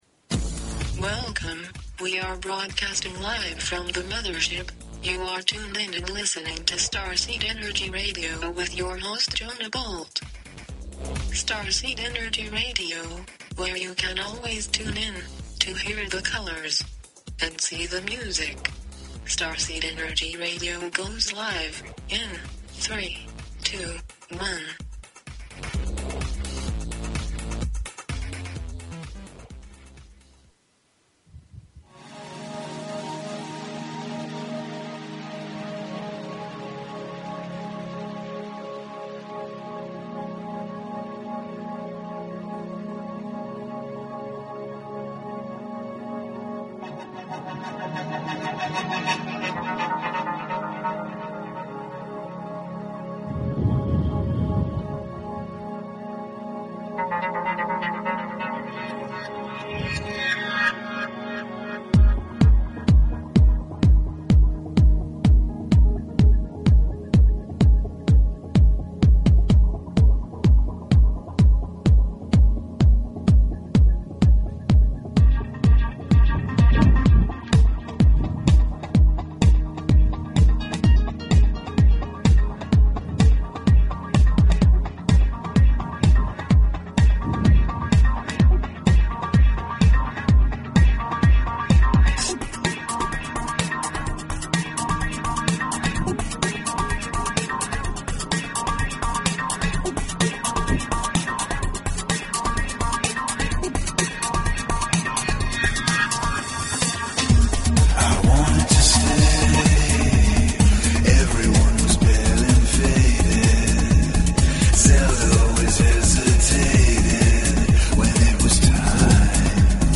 Talk Show Episode, Audio Podcast, Starseed_Energy_Radio and Courtesy of BBS Radio on , show guests , about , categorized as
GUEST - JACQUE FRESCO - FUTURIST